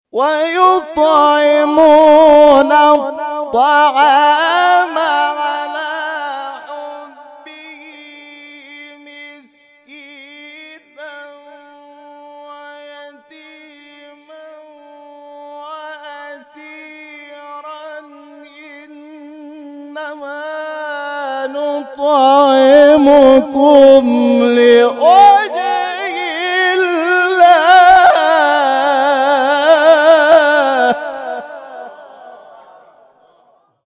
در ادامه قطعات تلاوت این کرسی ها ارائه می‌شود.